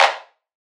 archived music/fl studio/drumkits/bvker drumkit/Claps